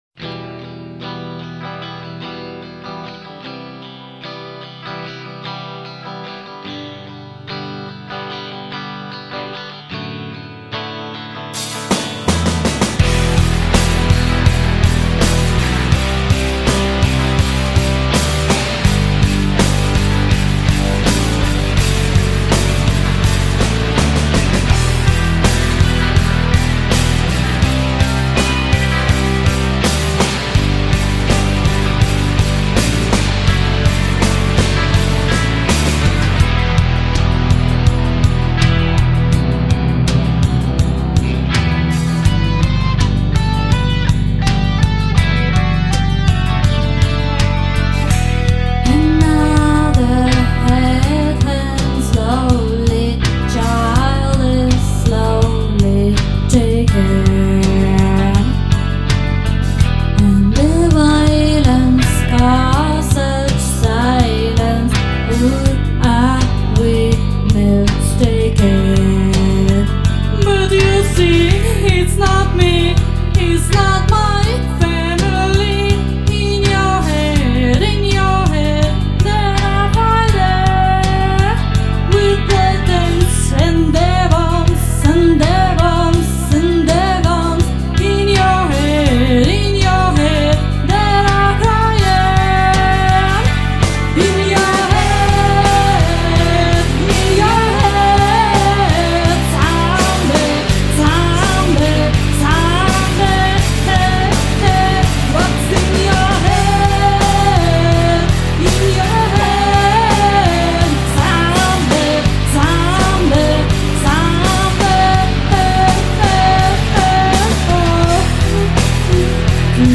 Уже с первых же фраз мимо нот.
Его можно было бы смягчить, не слишком рыча.))